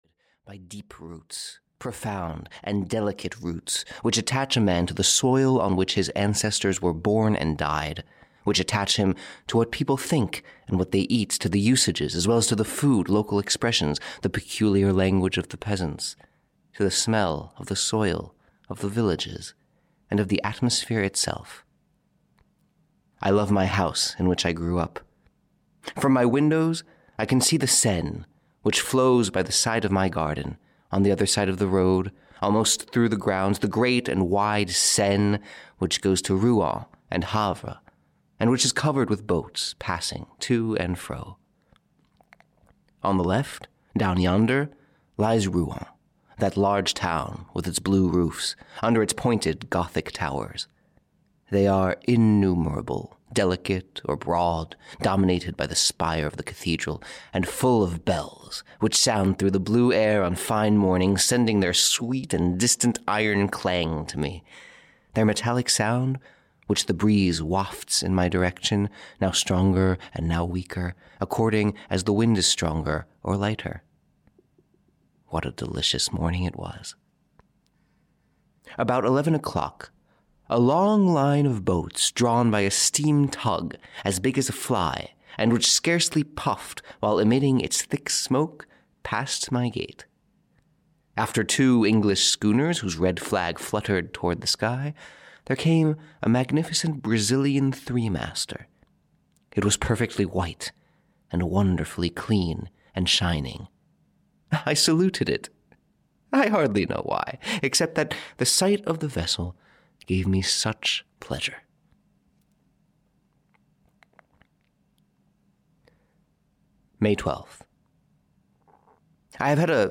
The Horla (EN) audiokniha
Ukázka z knihy